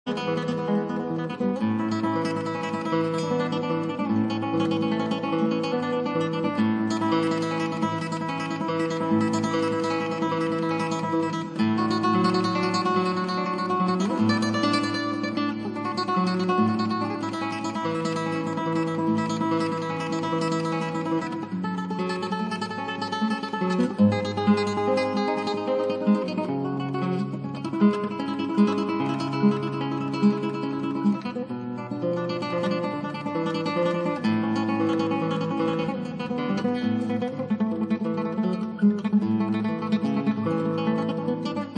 ギター演奏「アルハンブラの思いで」